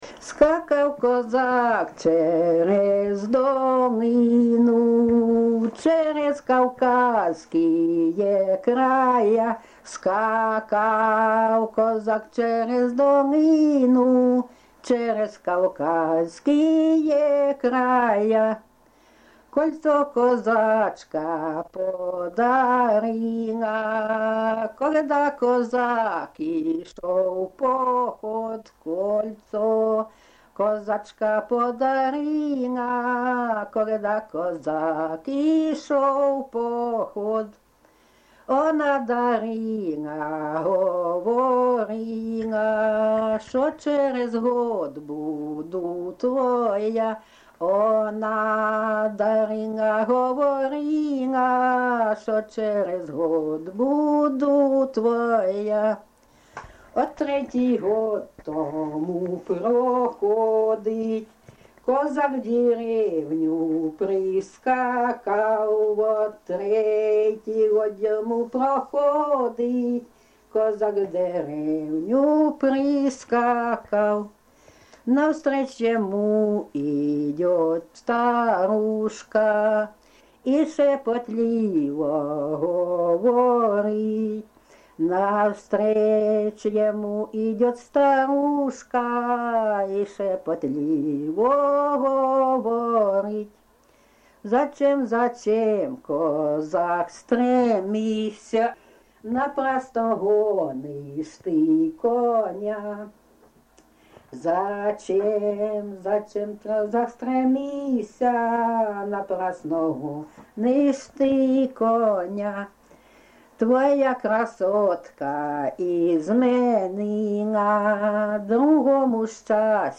ЖанрПісні з особистого та родинного життя, Козацькі
Місце записус. Курахівка, Покровський район, Донецька обл., Україна, Слобожанщина